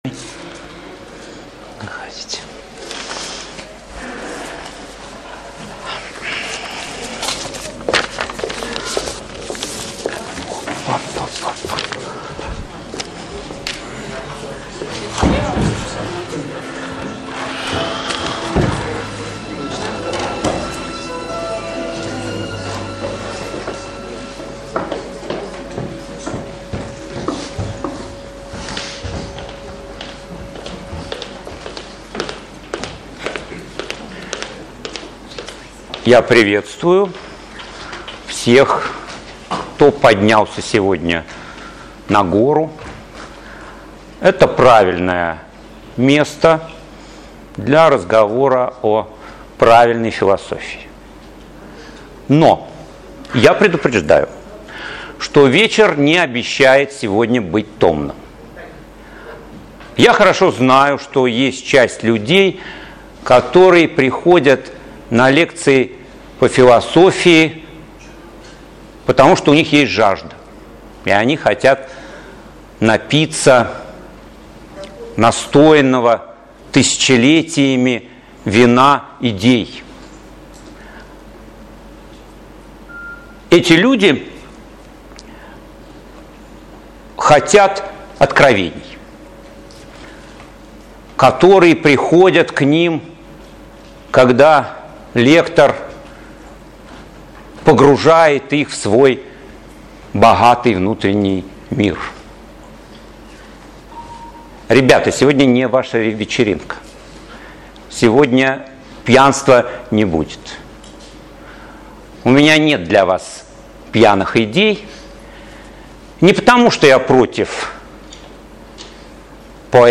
Аудиокнига О пользе и вреде философии для жизни | Библиотека аудиокниг